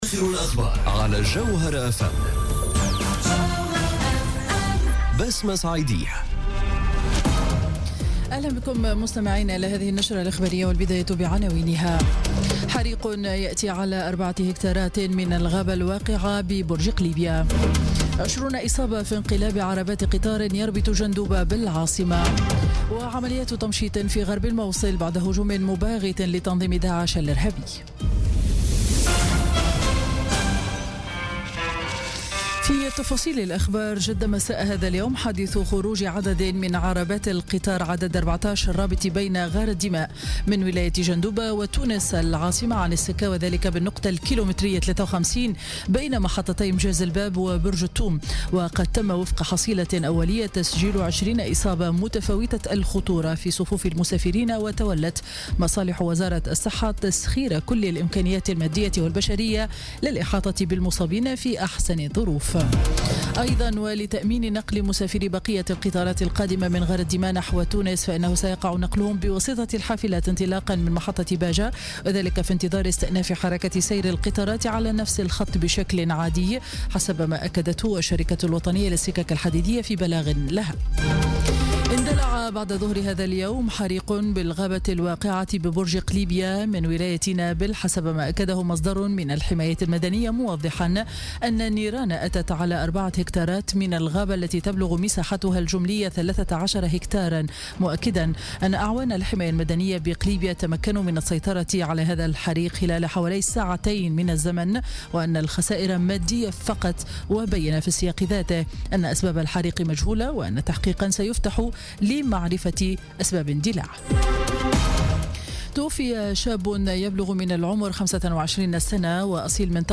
نشرة أخبار السابعة مساء ليوم الاثنين 26 جوان 2017